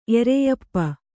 ♪ ereyappa